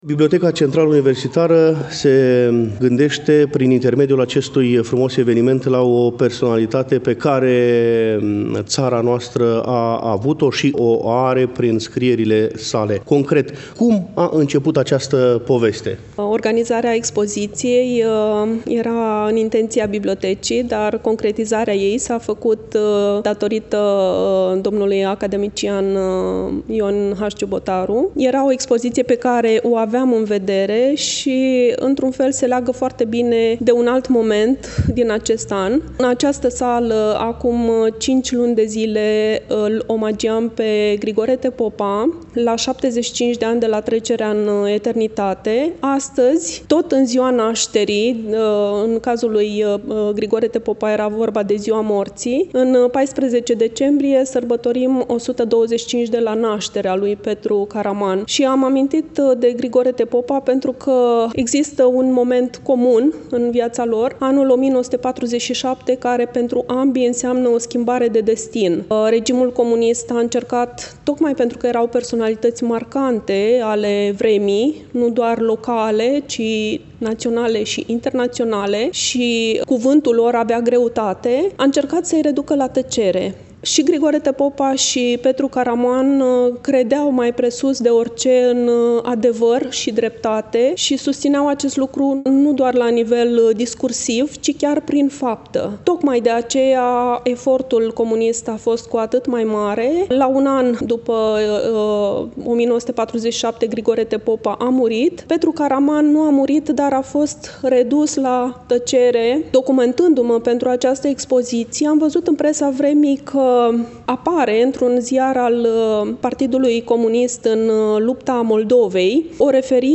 Cu prilejul împlinirii, la 14 decembrie 2023, a 125 de ani de la nașterea cel mai mare etnolog român, la Iași, în Sala „Hasdeu” din incinta BCU „Mihai Eminescu”, a fost organizat un eveniment de înaltă ținută academică.